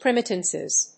音節prim･i･tive･ness発音記号・読み方prɪ́mətɪvnəs